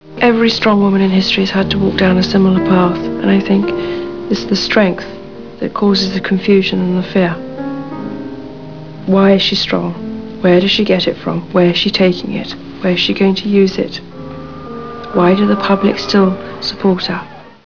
listen to Diana speak